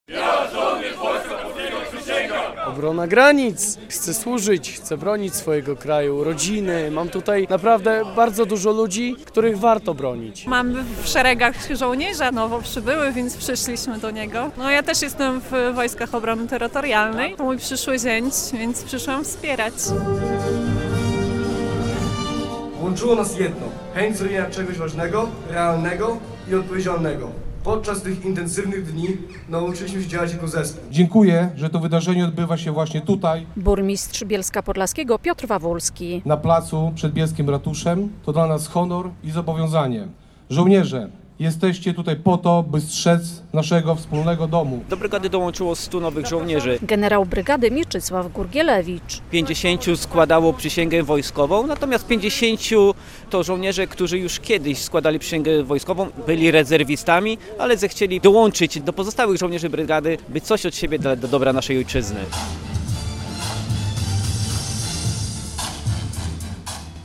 To ostatnia tegoroczna przysięga podlaskich terytorialsów. Na Placu Ratuszowym w Bielsku Podlaskim 100 ochotników przysięgało służyć Rzeczpospolitej Polskiej i bronić jej granic.